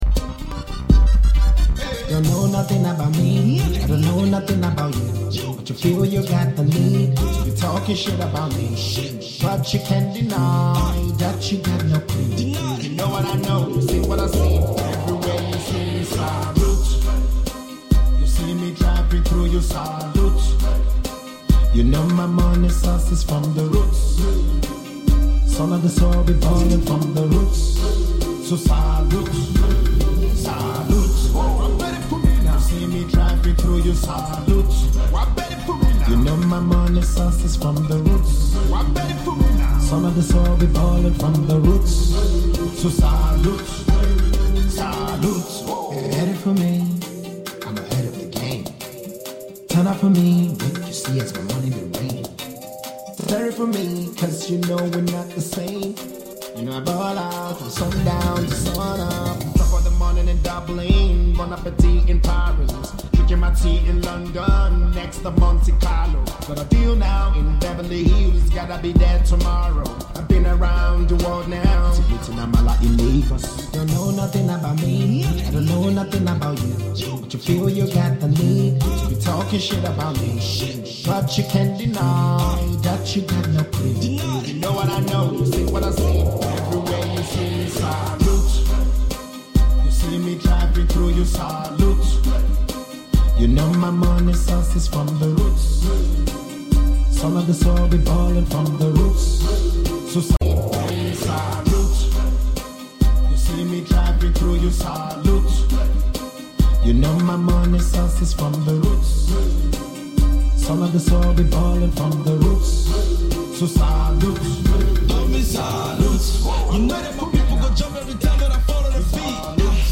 New hit track from afrobeats superstar and wonderboy